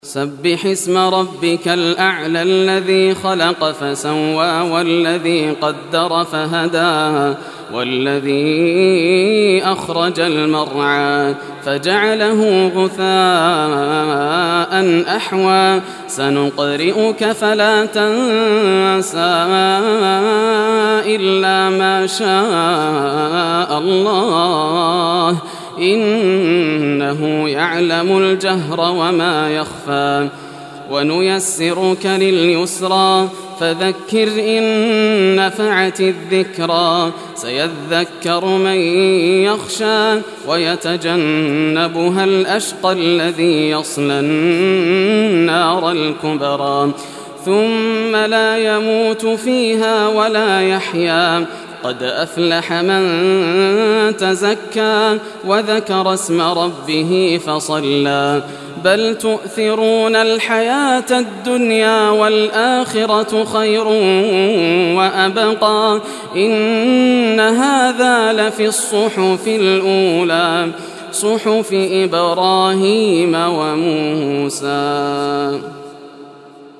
Surah Al-Ala Recitation by Yasser al Dosari
Surah Al-Ala, listen or play online mp3 tilawat / recitation in Arabic in the beautiful voice of Sheikh Yasser al Dosari.